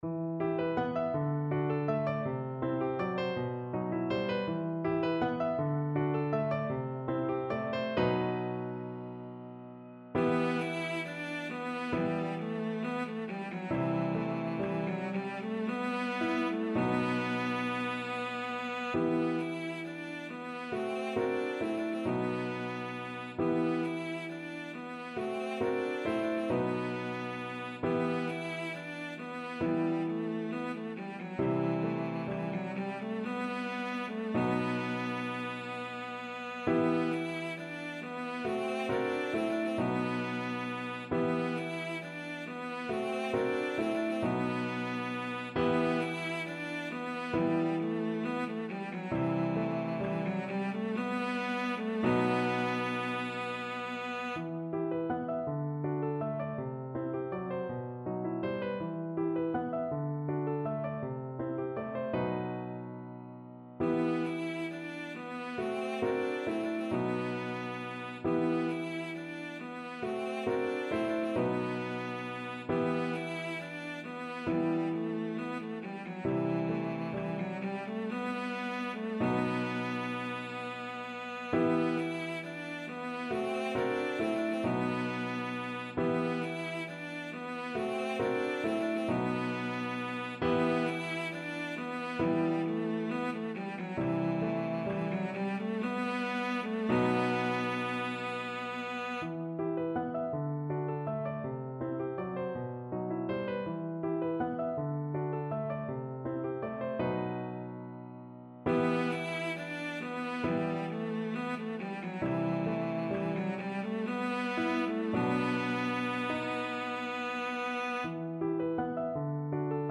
3/8 (View more 3/8 Music)
. = 54 Allegro no mucho (View more music marked Allegro)
E4-E5
Classical (View more Classical Cello Music)